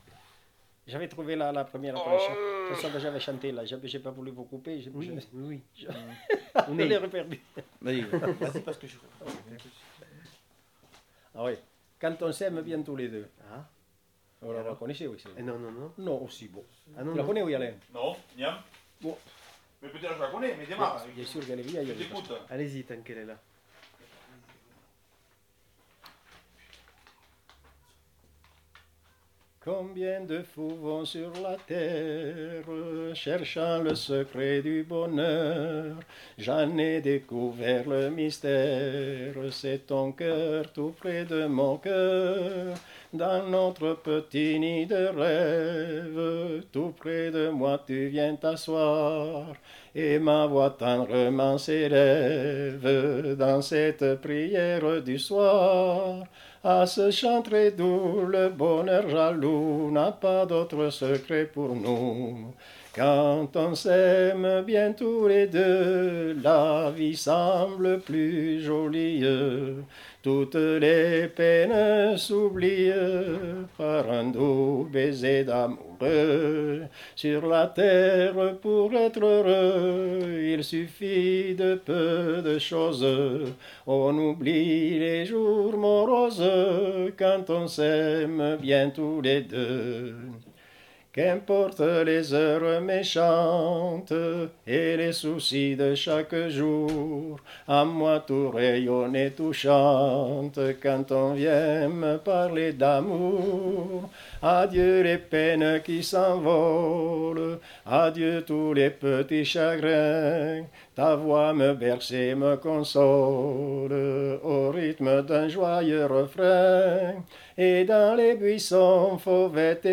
Genre : chant
Effectif : 1
Type de voix : voix d'homme
Production du son : chanté
Danse : valse